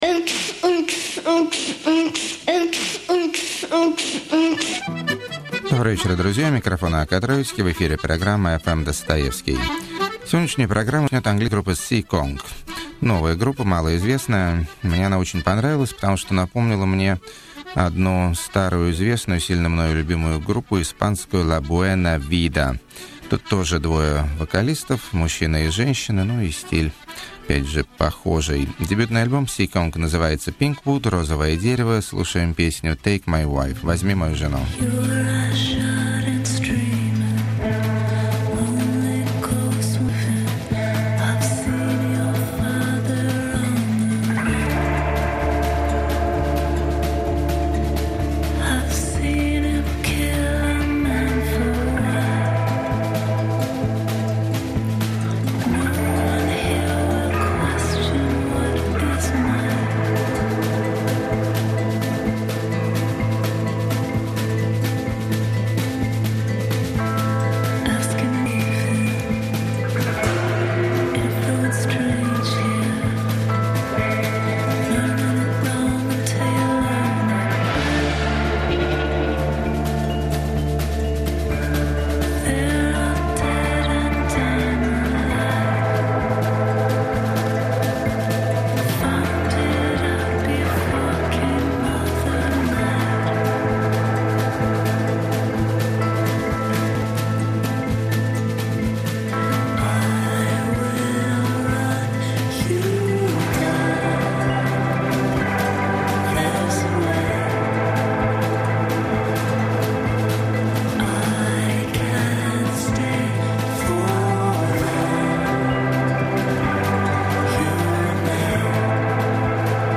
Качество плохое из-за проблем с записью.